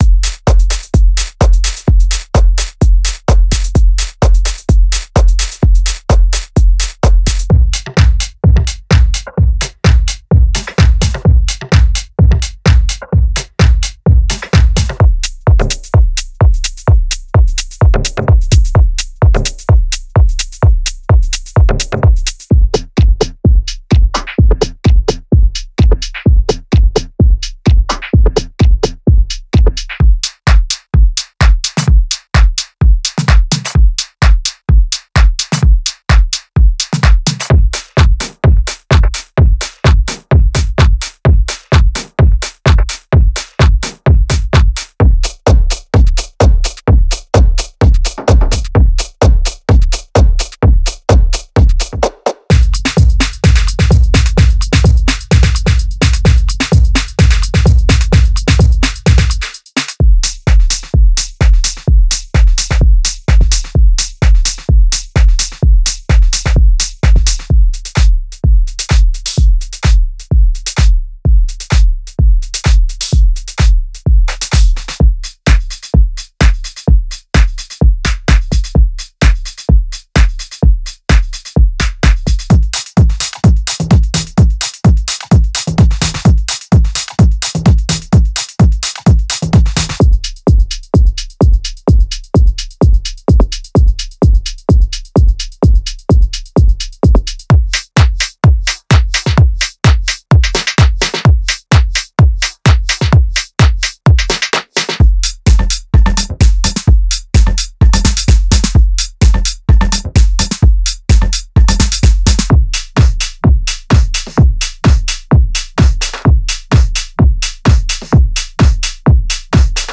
Tech House
A premium collection of the latest drum loops and drum hits for modern Tech House and House Producers.
A solid collection of punchy, deep, warm and cutting drum loops and samples designed for the latest trends and classic vibes in Tech House and House!
• Tempo 128 BPM
36 HiHat Loops